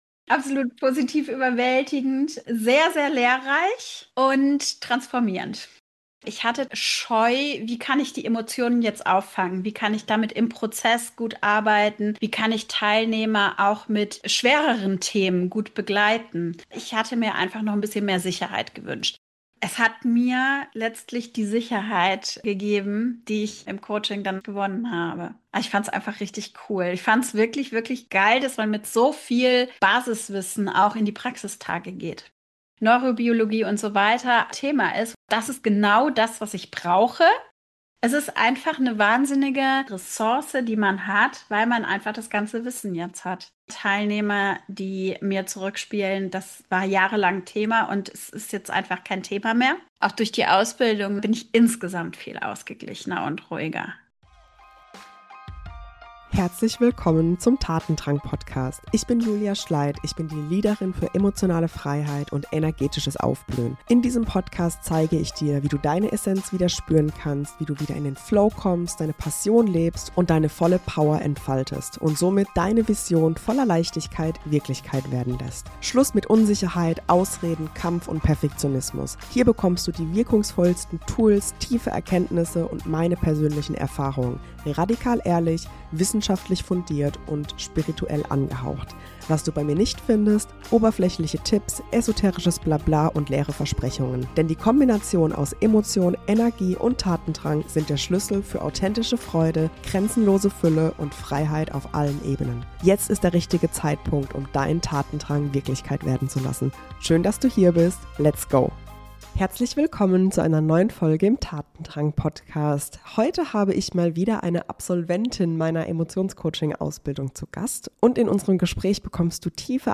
Warum Emotionscoaching der Gamechanger in der Kindheitsaufarbeitung ist | Interview